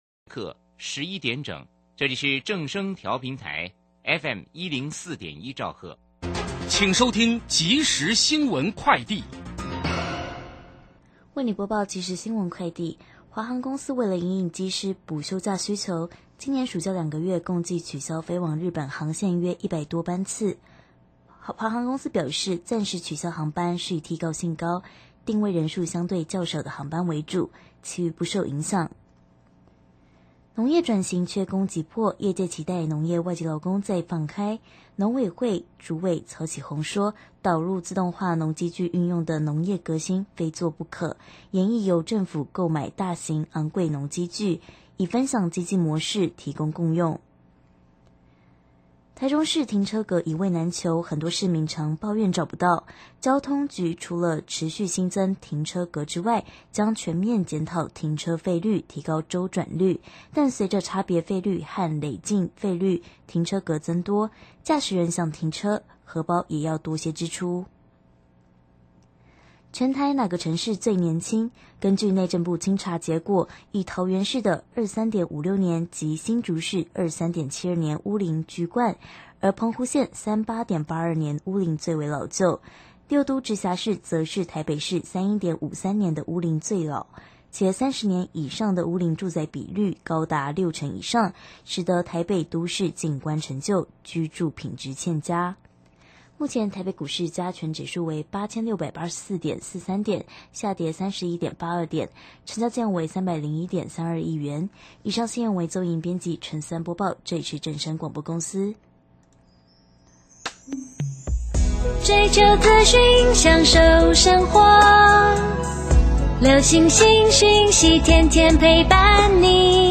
受訪者